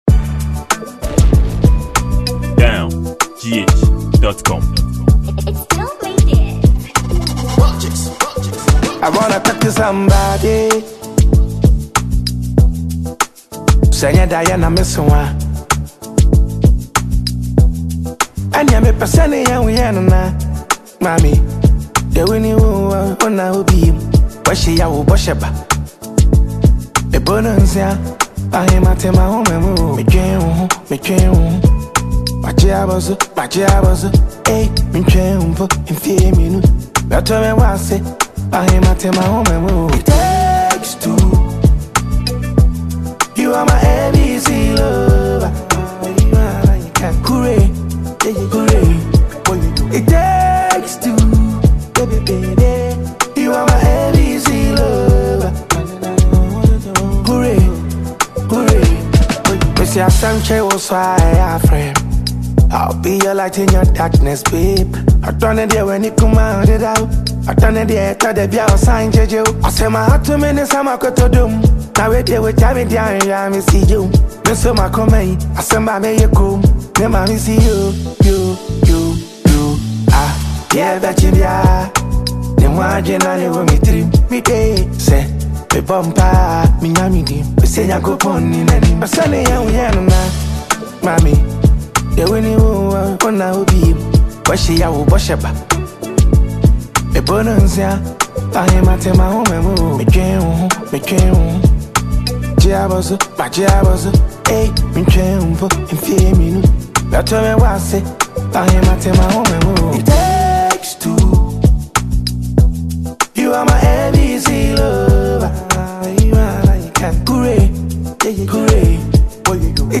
Genre: Highlife